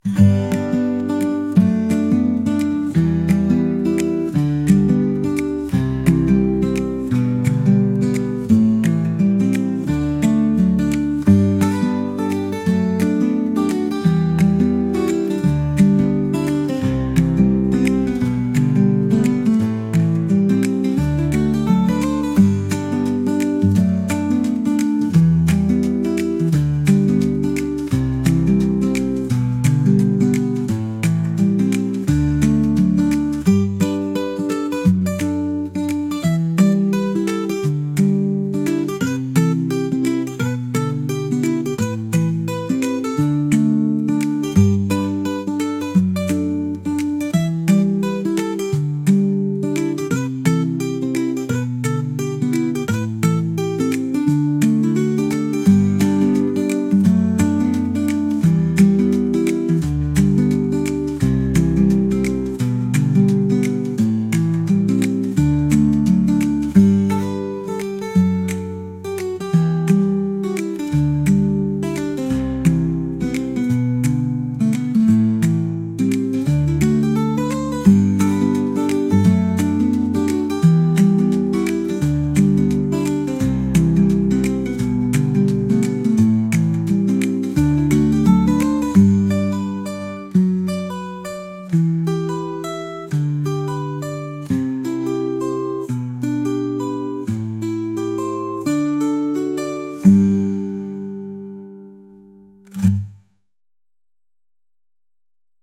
A Jazz track with a Happy, Calm atmosphere.
AI-generated.